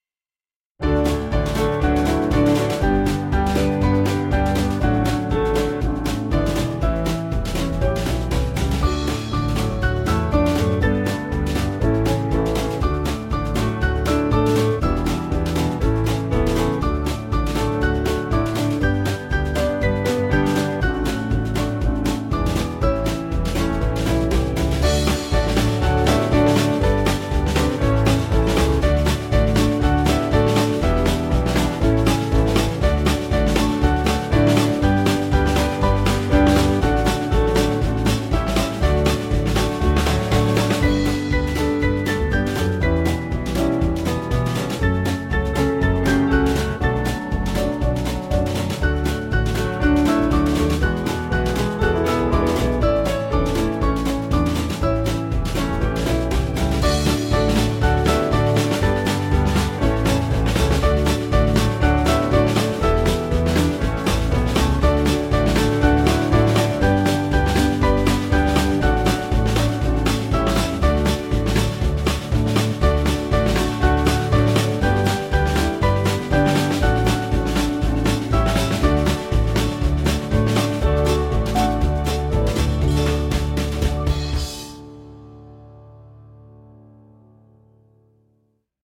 Yiddish folk tune
Small Band